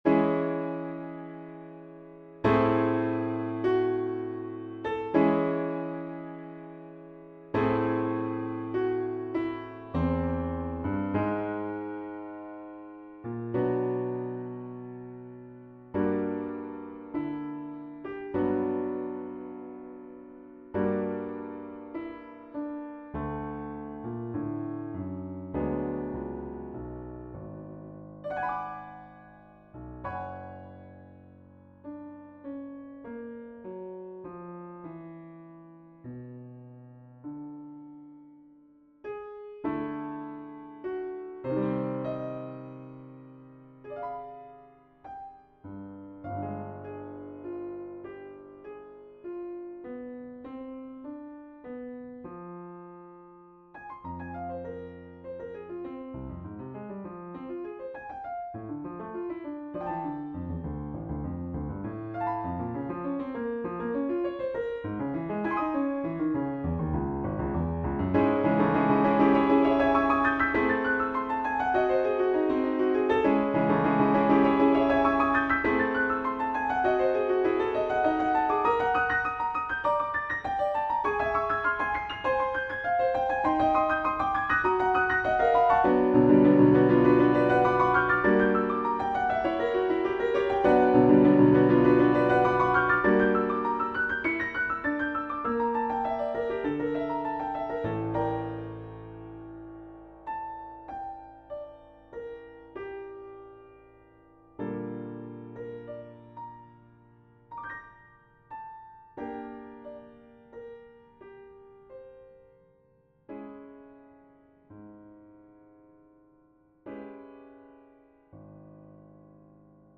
Here's my latest piece for piano, that I spent the last two days writing. Haven't quite finished the phrasing and dynamics yet.